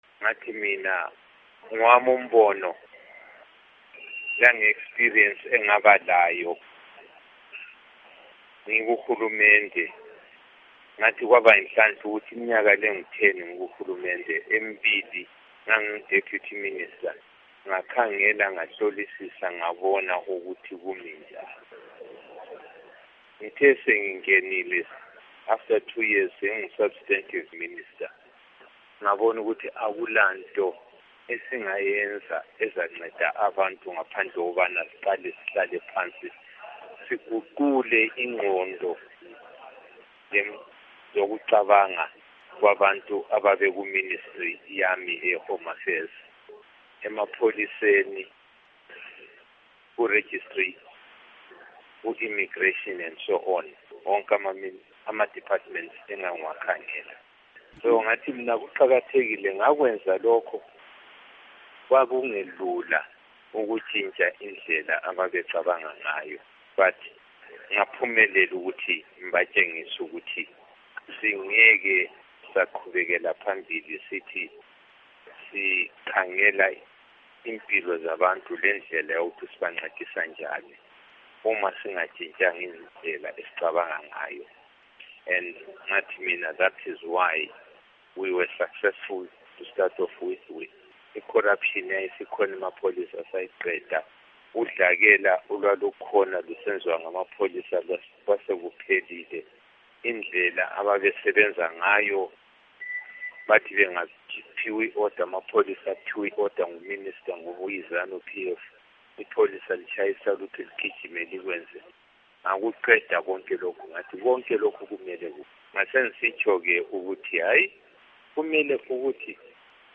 Ingxoxo LoMnu. Dumiso Dabengwa